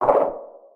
Sfx_creature_penguin_hop_voice_03.ogg